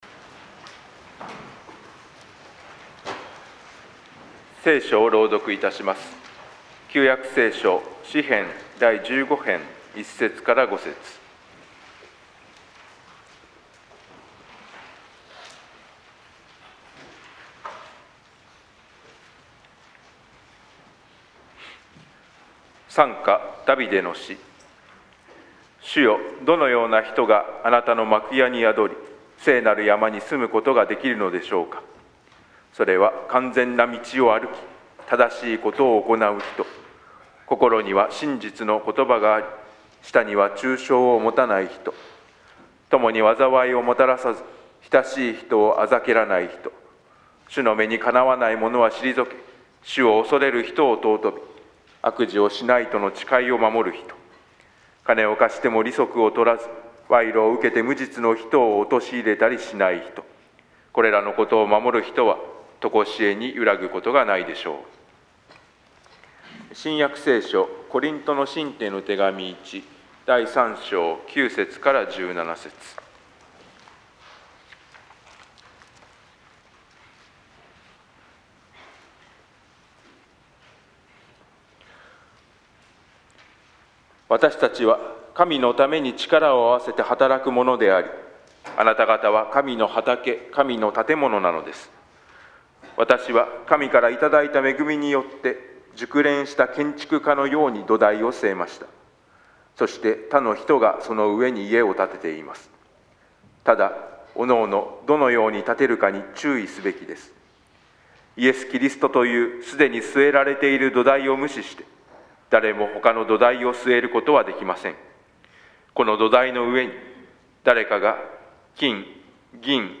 説教題